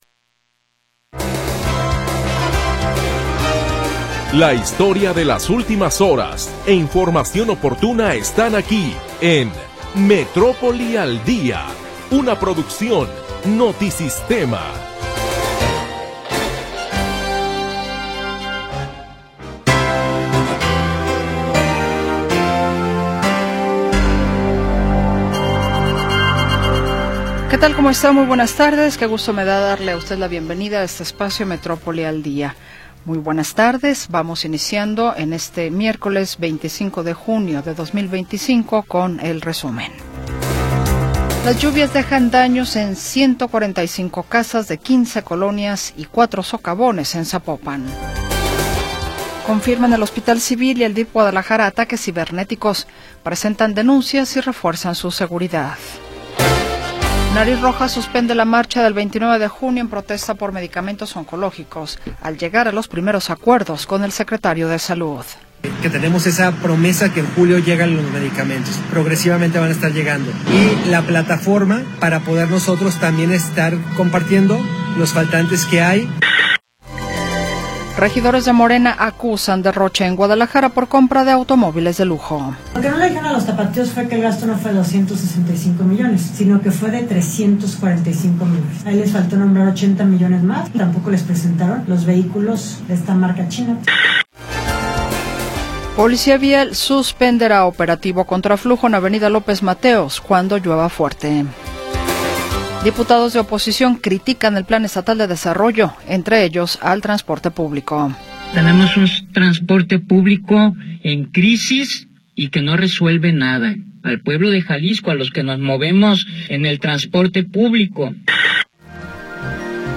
Primera hora del programa transmitido el 25 de Junio de 2025.